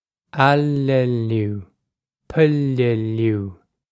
Ah-lih-lew, pih-lih-lew
This is an approximate phonetic pronunciation of the phrase.